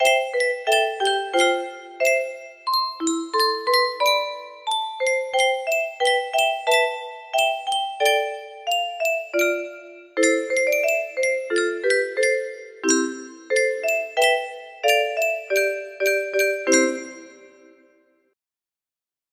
Waltzing Matilda 101 music box melody
Wow! It seems like this melody can be played offline on a 15 note paper strip music box!